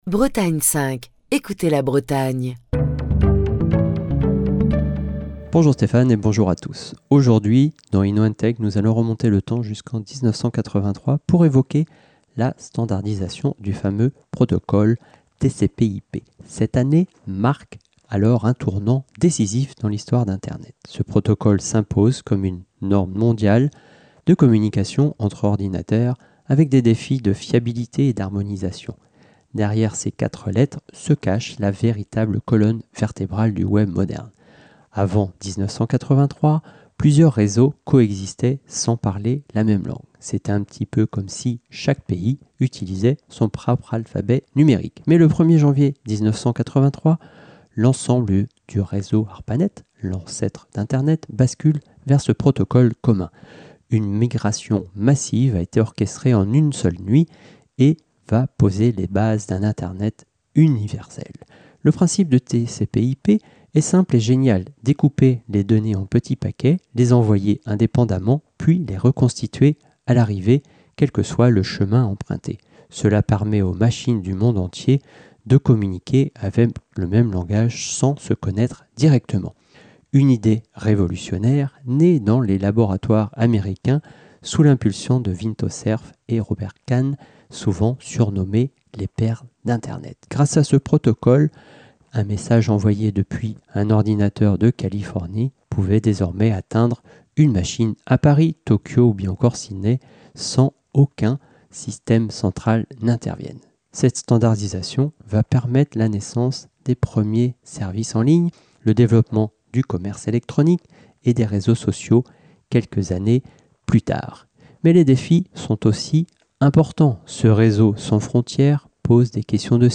Chronique du 20 novembre 2025.